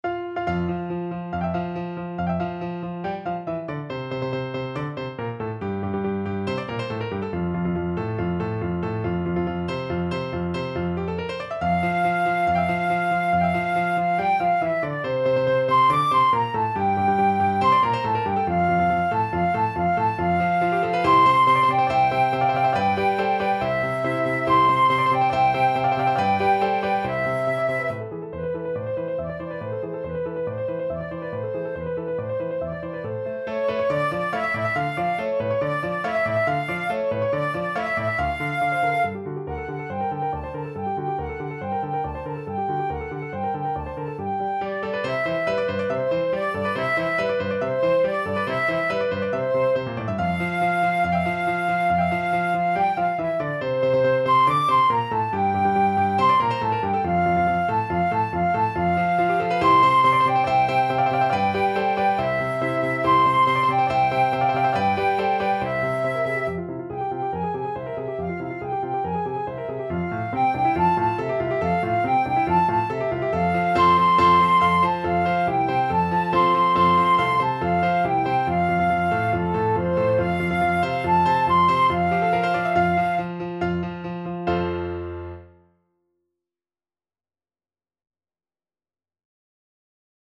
Flute
~ = 140 Allegro vivace (View more music marked Allegro)
F major (Sounding Pitch) (View more F major Music for Flute )
2/4 (View more 2/4 Music)
Classical (View more Classical Flute Music)